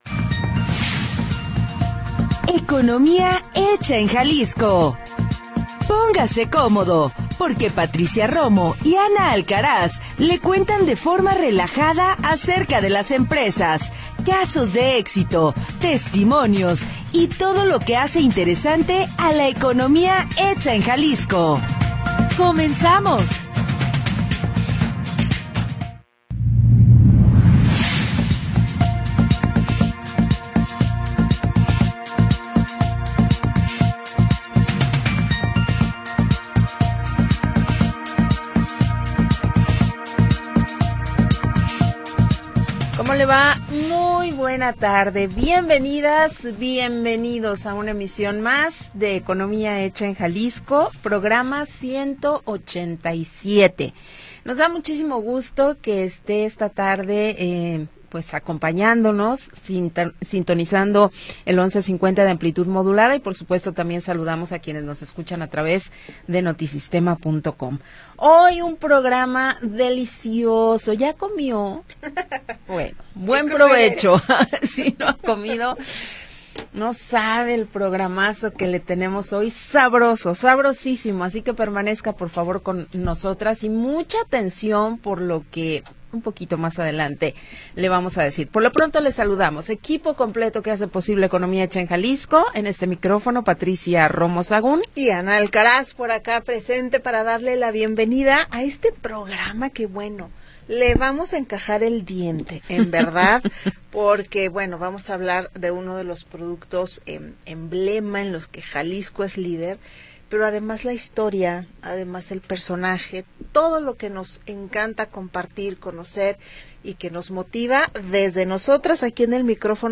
le cuentan de forma relajada
Programa transmitido el 20 de Noviembre de 2025.